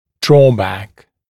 [‘drɔːbæk][‘дро:бэк]недостаток, отрицательная сторона